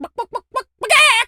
chicken_cluck_to_scream_04.wav